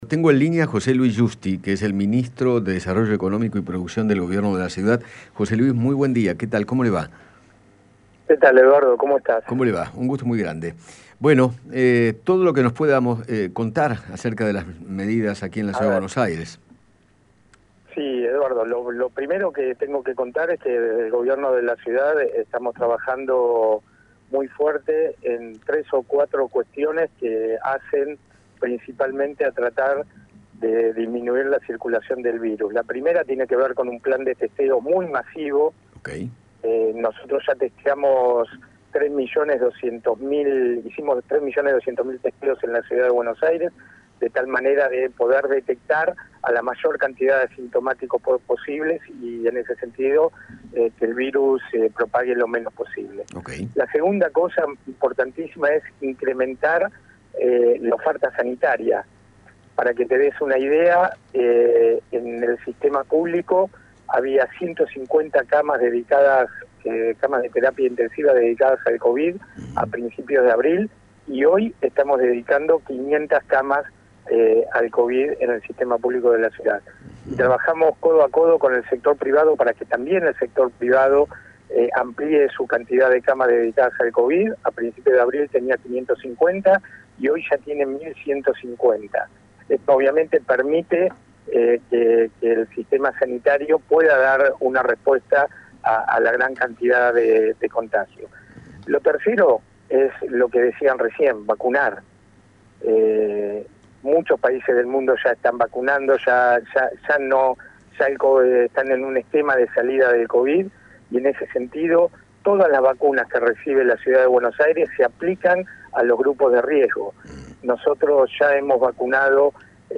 José Luis Giusti, ministro de Desarrollo Económico y Producción de la Ciudad, dialogó con Eduardo Feinmann sobre las nuevas restricciones y se refirió las cuestiones en las que trabajarán de manera más intensa.